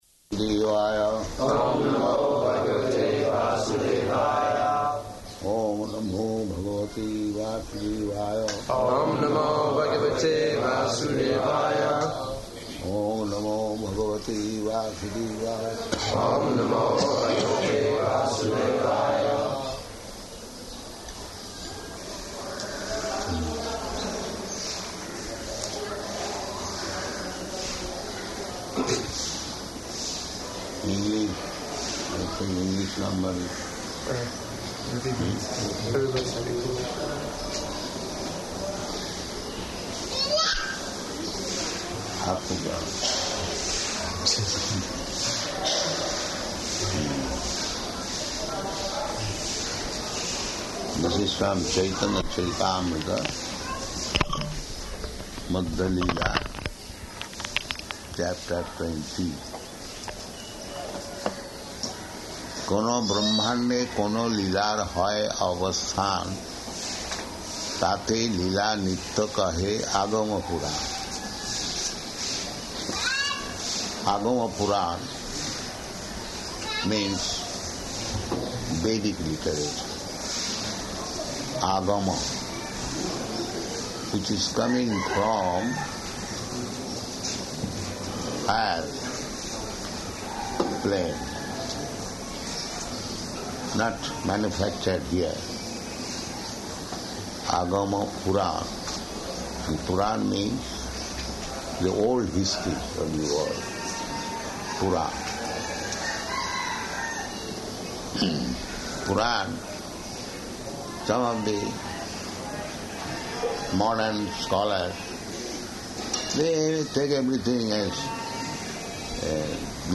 Śrī Caitanya-caritāmṛta, Madhya-līlā 20.395 --:-- --:-- Type: Caitanya-caritamrta Dated: August 17th 1976 Location: Hyderabad Audio file: 760817CC.HYD.mp3 Prabhupāda: Oṁ namo bhāgavate vāsudevāya.